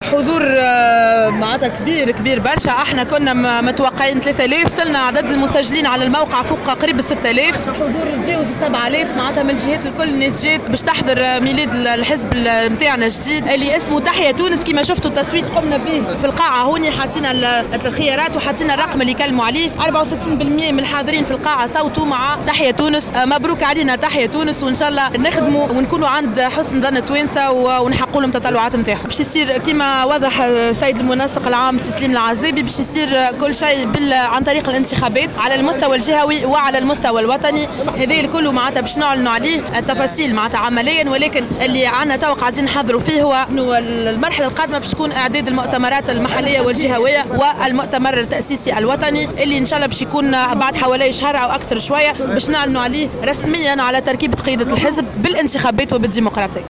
وأضافت في تصريح لمراسل "الجوهرة أف أم" أن كل القرارات سيتم اتخاذها عن طريق الانتخابات كما سيحدد المنسق العام سليم العزابي برنامج المرحلة القادمة التي ستشهد اعداد المؤتمرات المحلية والجهوية استعدادا للمؤتمر التأسيسي الوطني الذي سينعقد بعد نحو شهر للإعلان رسميا عن تركيبية قيادات الحزب، وفق تعبيرها.